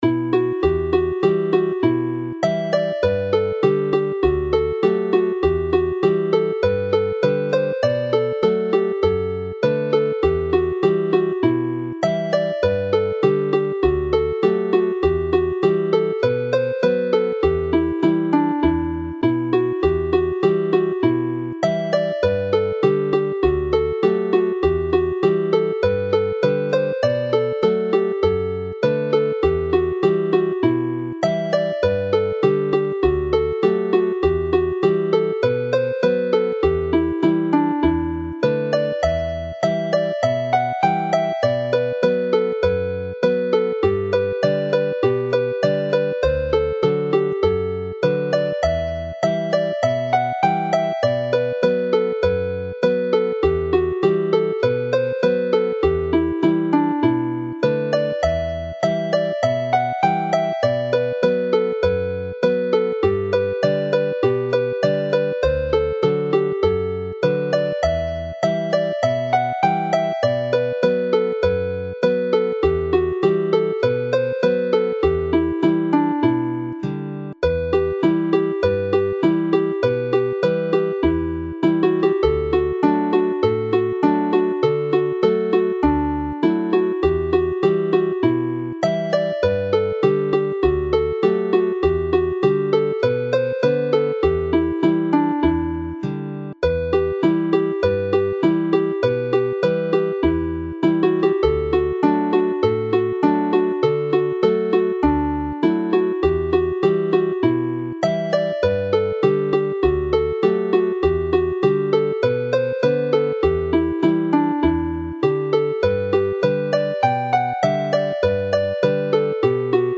Play the set slowly